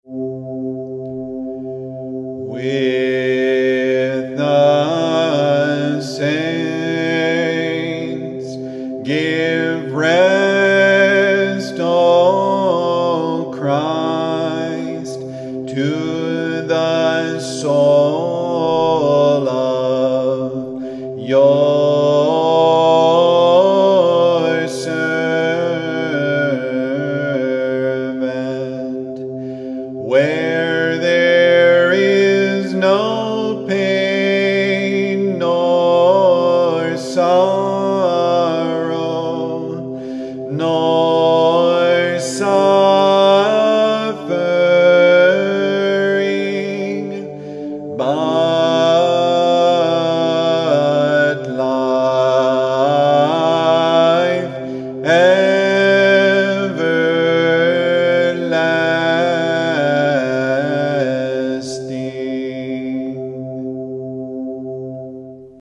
kontakion.mp3